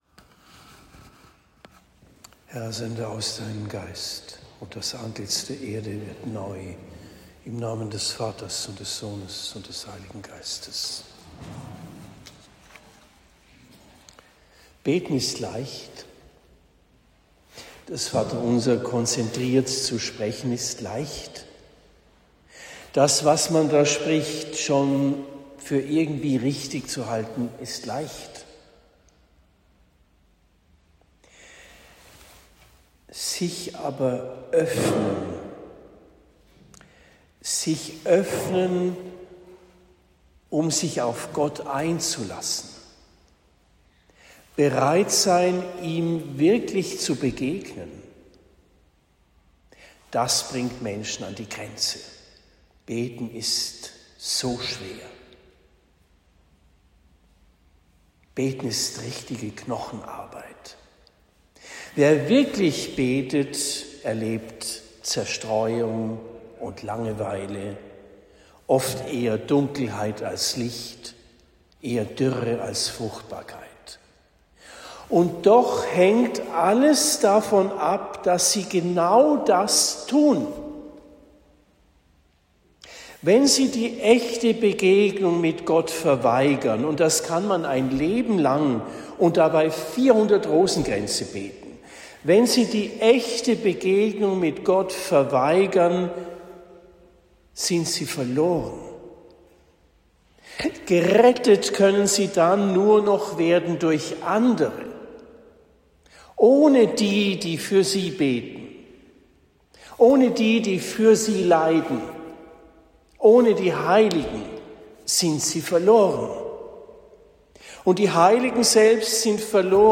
Predigt in Rettersheim am 25. März 2026